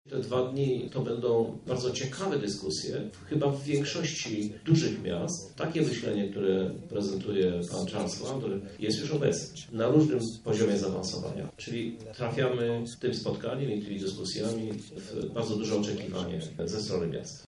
O szczegółach mówi Krzysztof Żuk- prezydent miasta Lublin